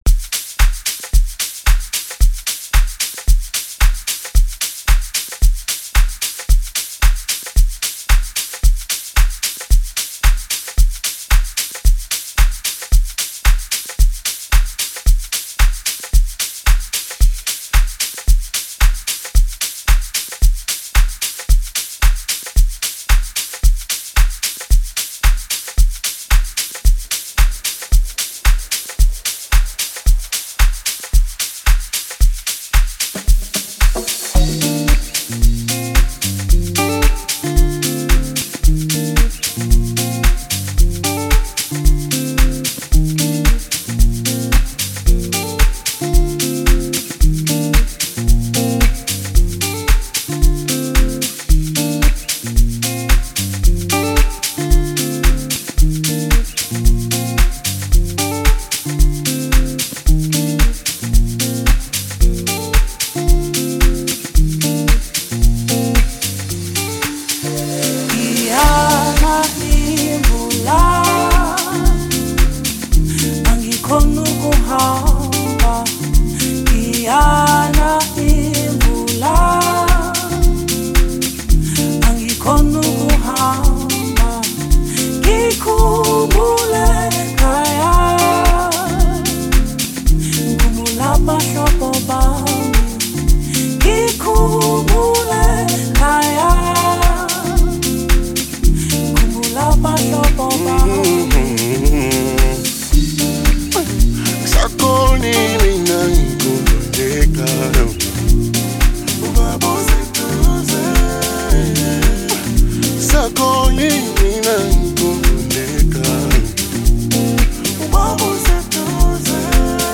52:09 Genre : Amapiano Size